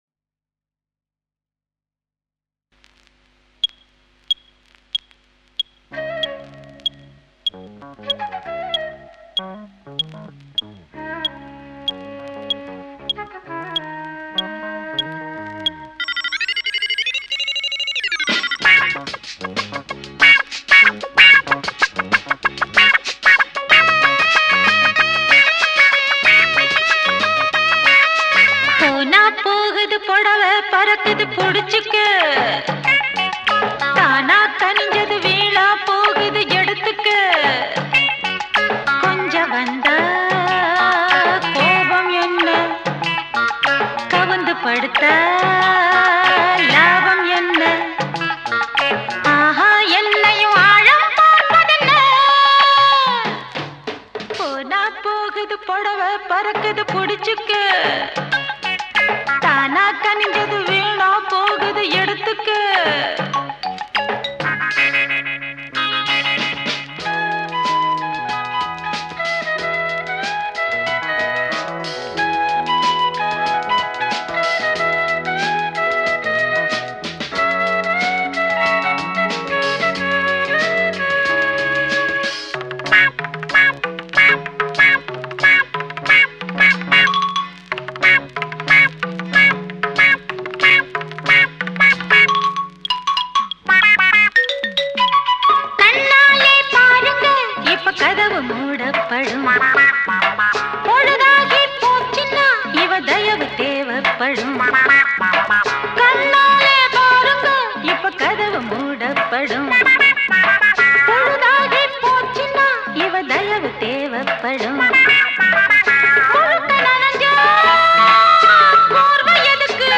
Tamil Movie Songs